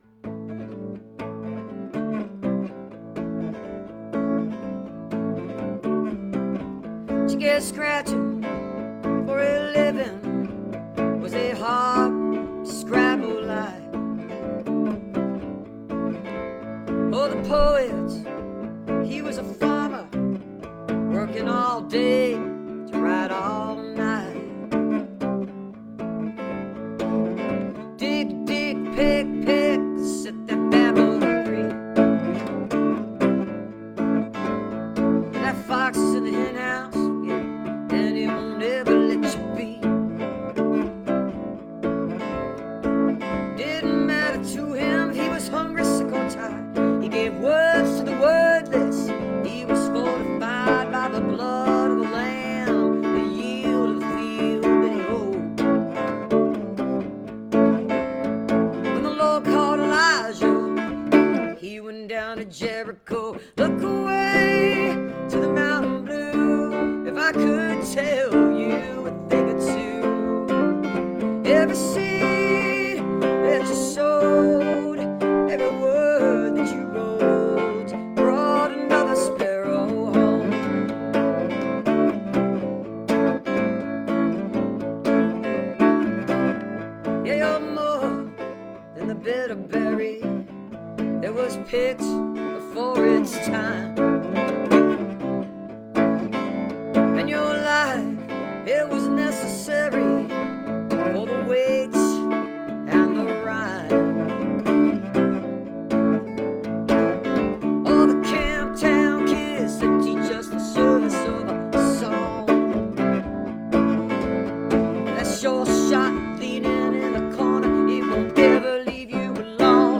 (captured from the facebook live stream)